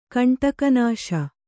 ♪ kaṇṭaka nāśa